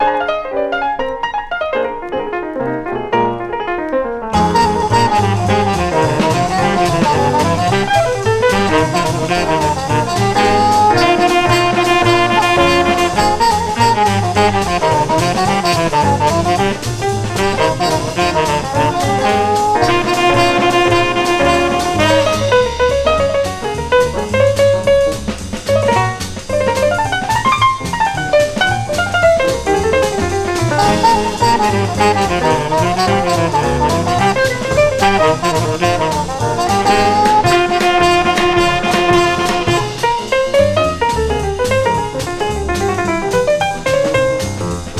本盤は、活動の指針とも思えるビッグ・バンド・ジャズで聴かせます。
粋とも思えるムードがなんとも心地よく音から溢れ、艶やかな音、彩り豊かなアレンジも素敵。
Jazz, Big Band　USA　12inchレコード　33rpm　Mono